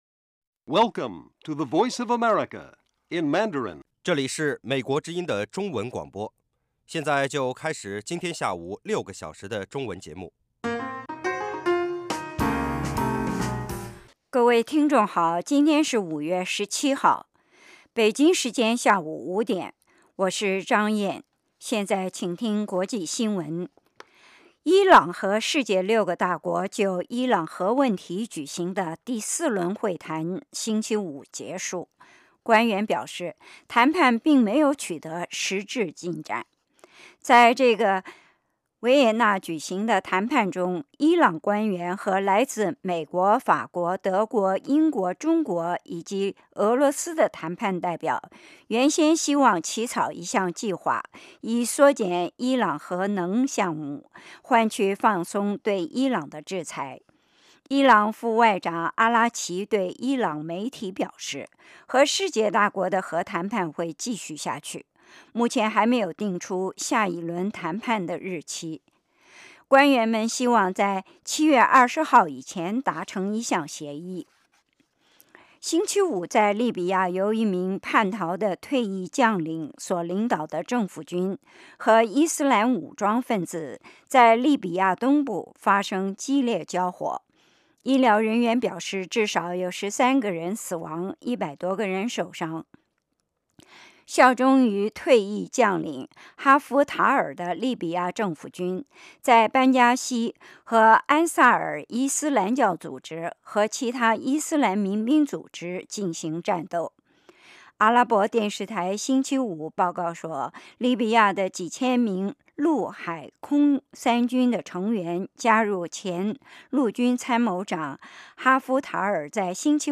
国际新闻 英语教学 社论 北京时间: 下午5点 格林威治标准时间: 0900 节目长度 : 60 收听: mp3